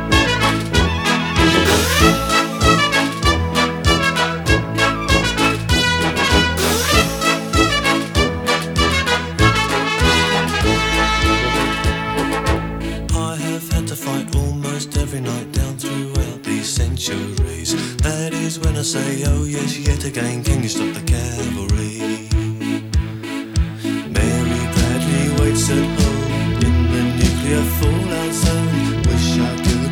• Christmas